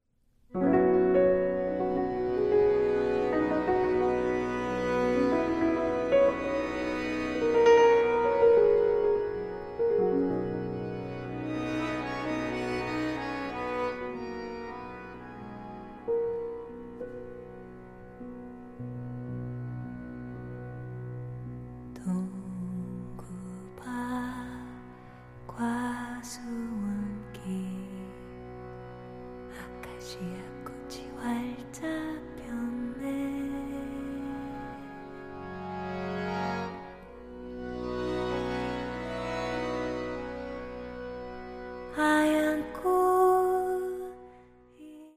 voice
piano
accordion